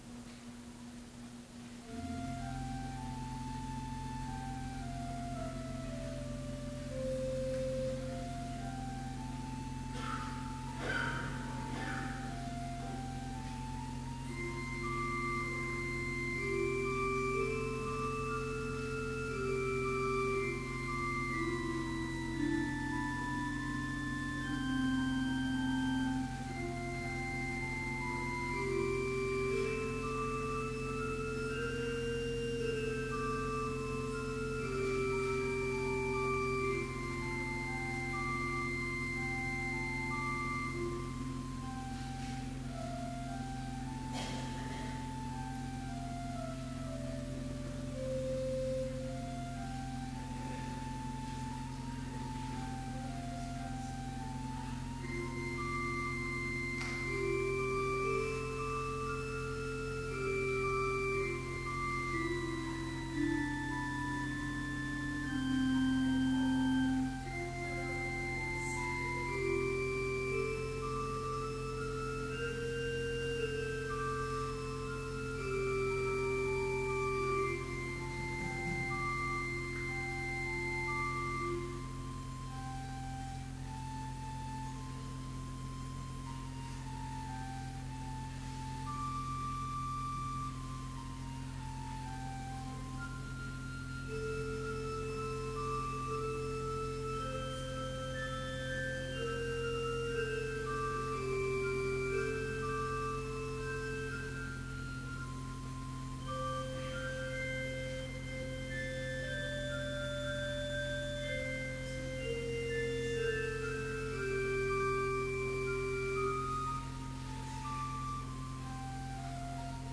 Complete service audio for Advent Vespers - December 19, 2012